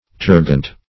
Search Result for " tergant" : The Collaborative International Dictionary of English v.0.48: Tergant \Ter"gant\, a. (Her.)